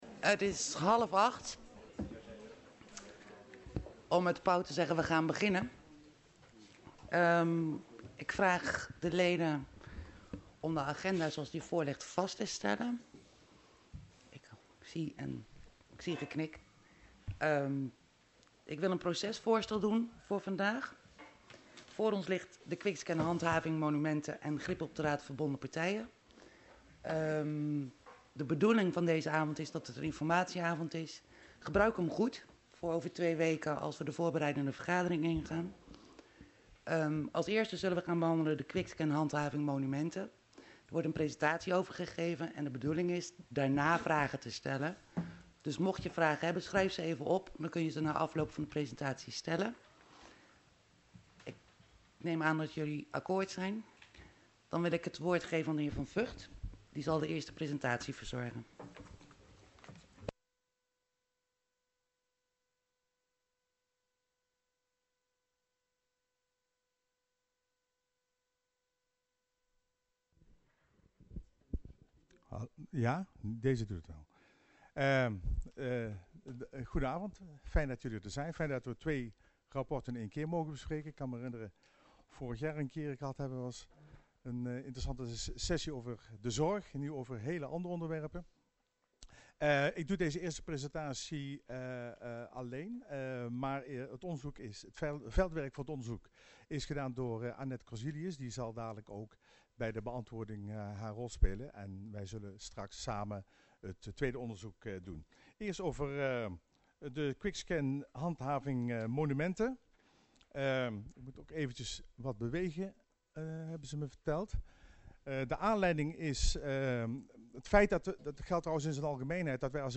Locatie Hal, gemeentehuis Elst Voorzitter mevr.